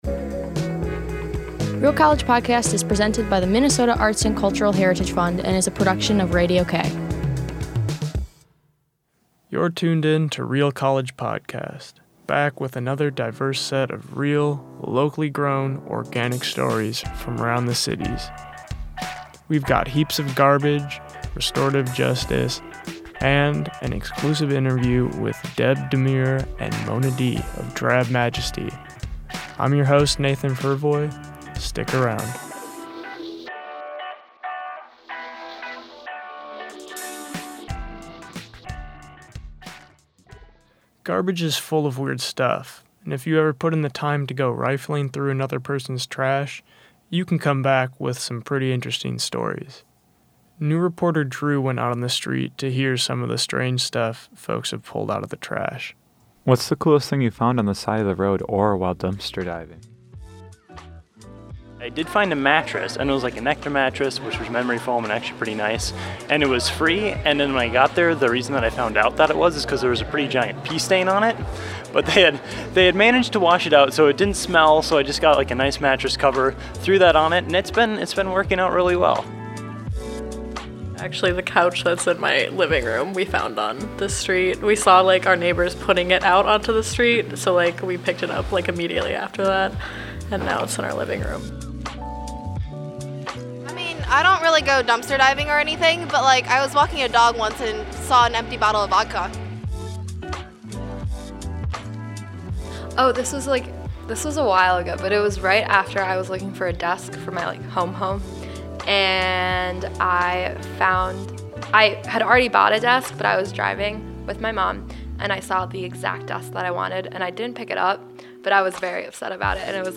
Ride along with the garbage man, learn about a local restorative justice program and listen to an exclusive interview with LA-based Drab Majesty.